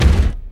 BattleCatBigkick2.wav